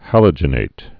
(hălə-jə-nāt)